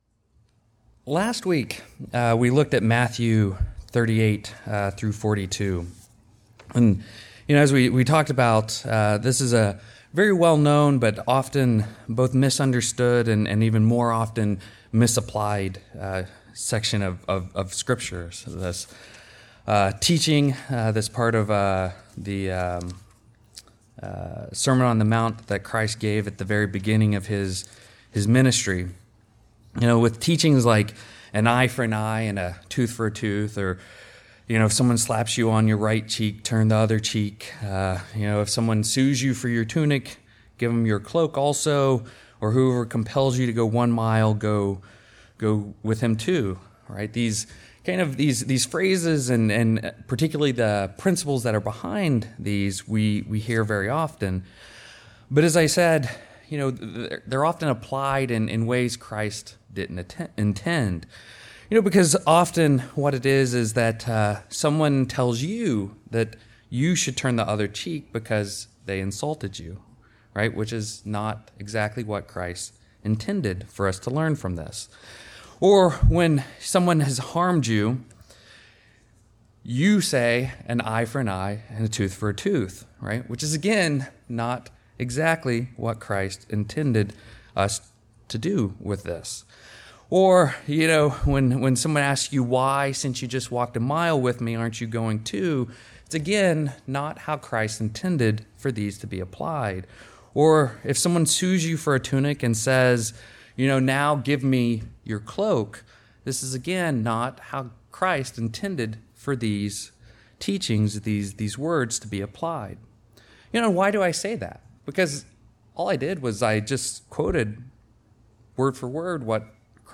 Sermons
Given in Seattle, WA Burlington, WA